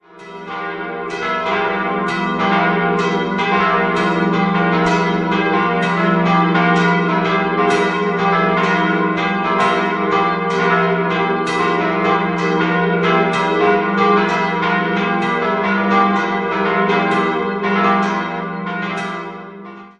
Die im Kern spätgotische Kirche erhielt im 18. Jahrhundert den barocken Turmaufsatz, 1957 erbaute man ein neues Langhaus nach den Plänen Friedrich Haindls. 4-stimmiges Geläut: e'-f'-g'-h' D ie große Glocke wurde 1861 von Anton Spannagl (Straubing) gegossen, die drei anderen 1950 von Gugg, ebenfalls in Straubing. Die offizielle Schlagtonfolge e'-fis'-gis'-h' wurde hörbar verfehlt.